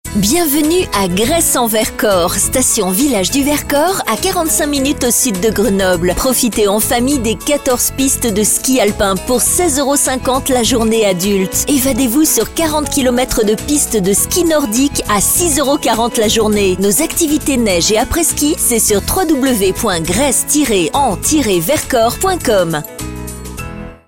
Native speaker Female 30-50 lat
Pleasant and delicate voice.
Nagranie lektorskie